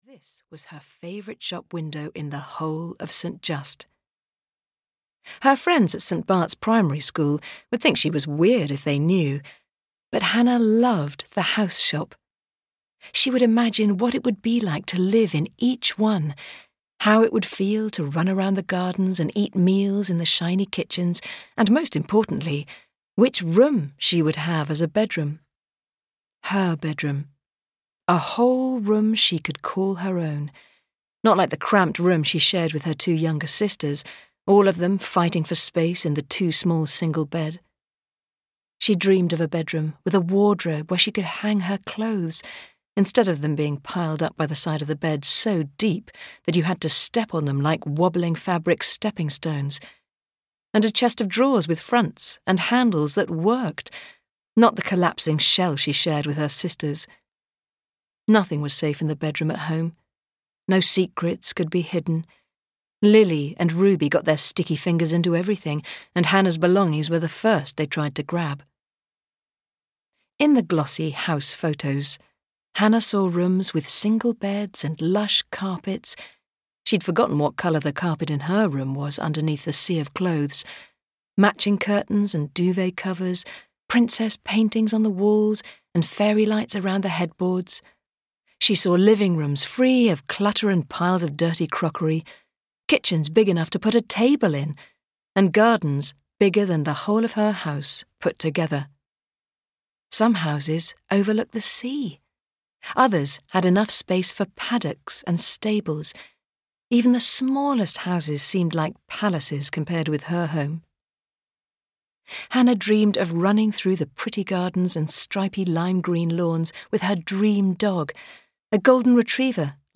The Secret Voices (EN) audiokniha
Ukázka z knihy